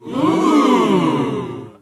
😂 More meme sounds and moaning sound effects in the AoM Oooh soundboard
aom-oooooh.mp3